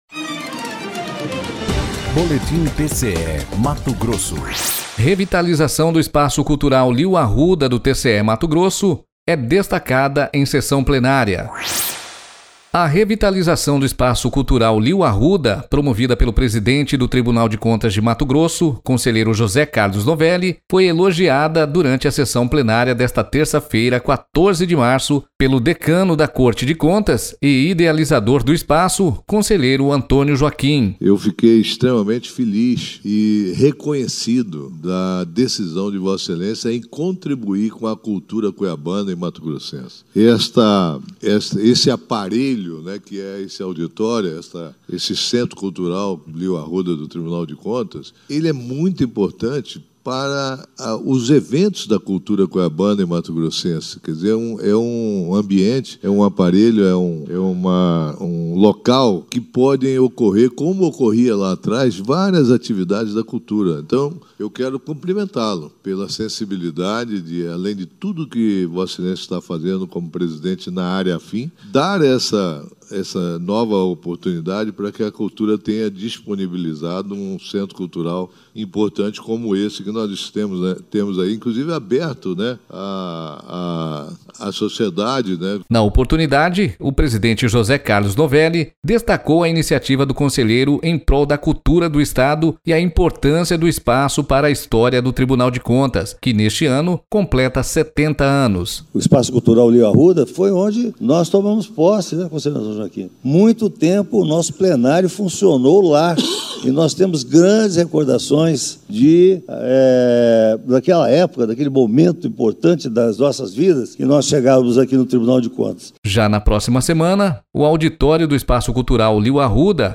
Sonora: Antonio Joaquim – conselheiro do TCE-MT
Sonora: José Carlos Novelli – conselheiro presidente do TCE-MT